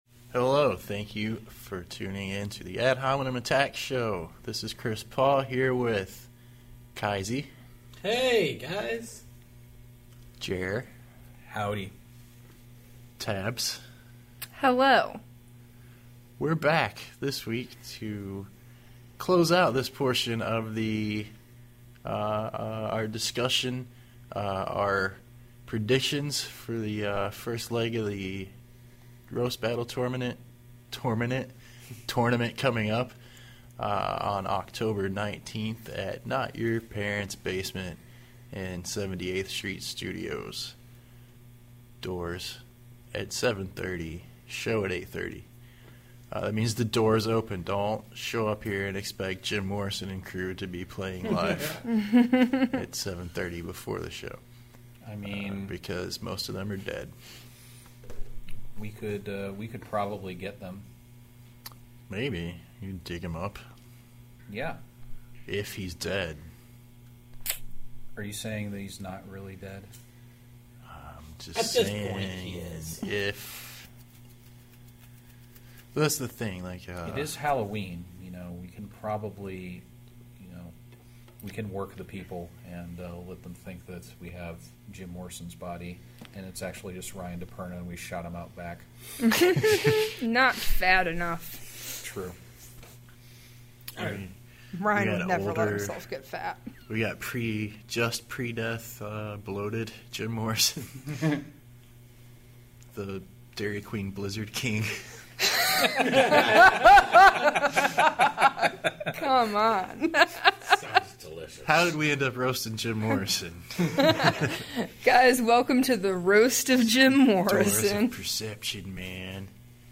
Recorded at Not Your Parents Basement.